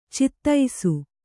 ♪ cittaisu